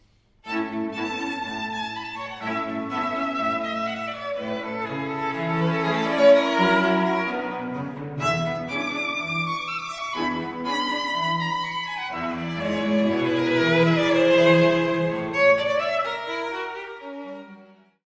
mp3Mayer, Emilie, String Quartet in A major, mvt. I, Un poco adagio, mm.18-25